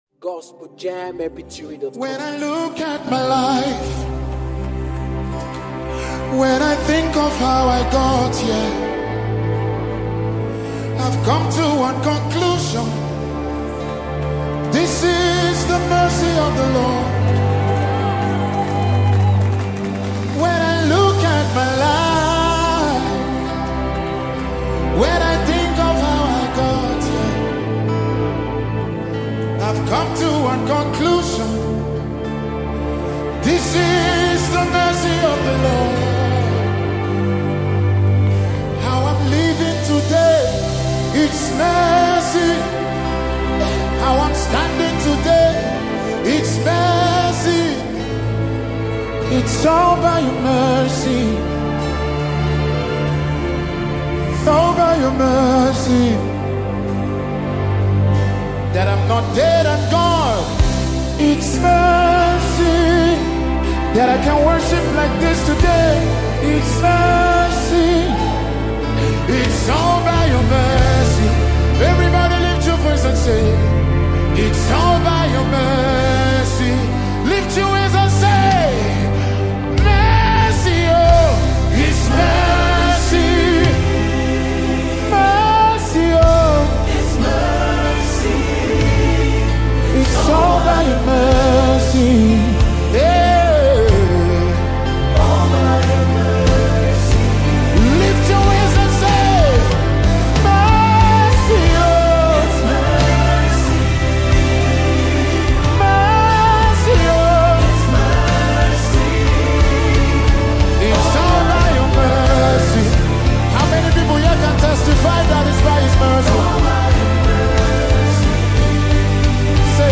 heartfelt worship anthem